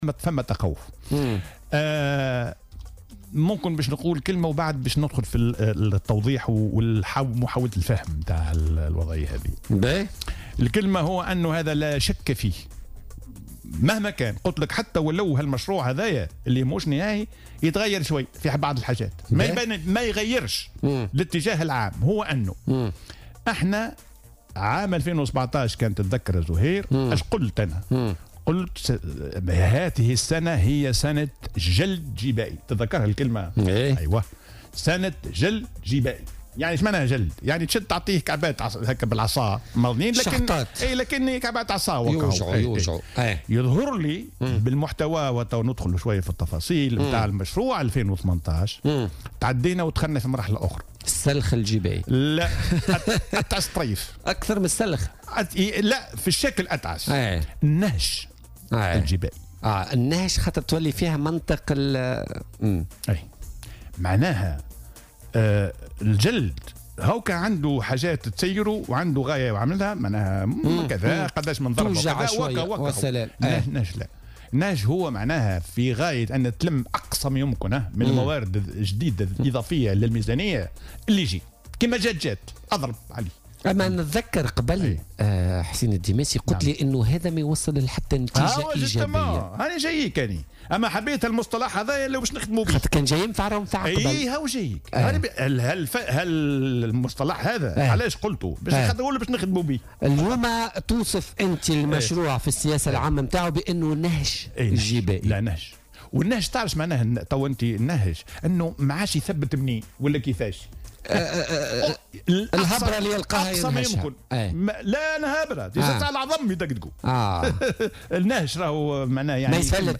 وأوضح في مداخلة له اليوم في برنامج "بوليتيكا" أن حاجيات ميزانية الدولة لسنة 2018 تقدّر بحوالي 12 مليار دينار، وهو ما سيجعل الحكومة في مأزق وتتجه نحو الترفيع في الأداءات والضرائب، خاصة في ظل وجود صعوبات للحصول على تمويلات خارجية، وفق تعبيره.